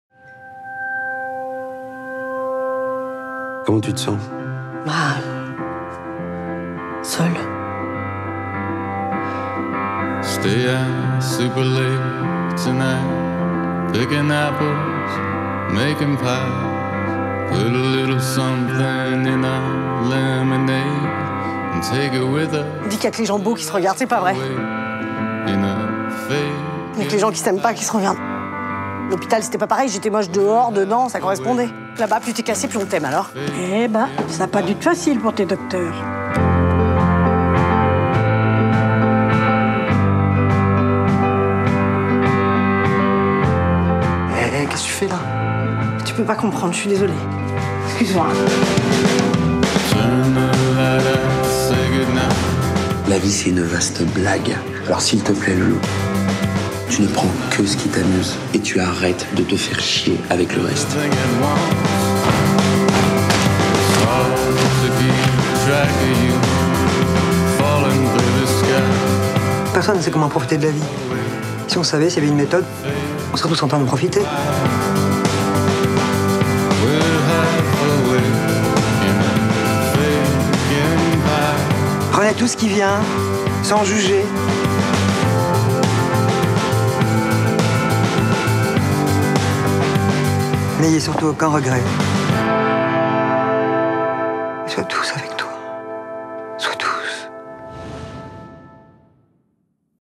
DE-PLUS-BELLE-Bande-annonce-officielle-Florence-Foresti-Mathieu-Kassovitz-2017.mp3